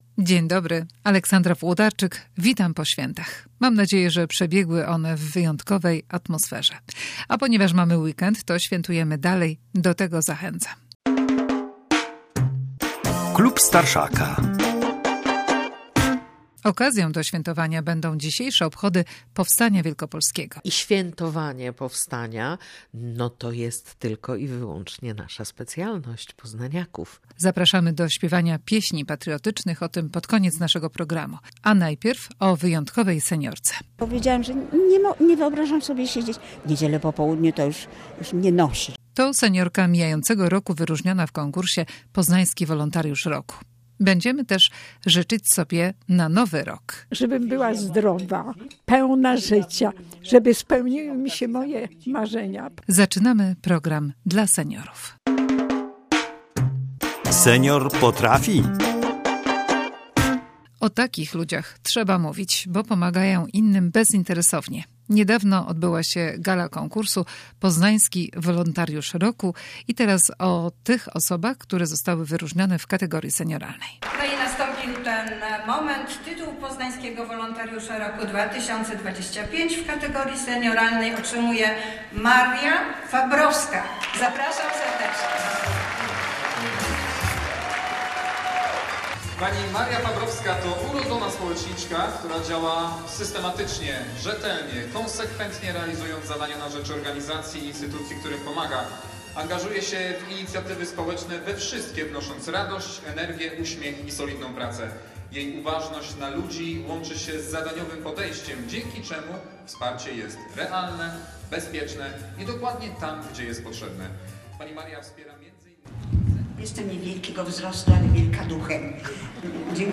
W programie relacja z gali i rozmowa ze zwyciężczynią.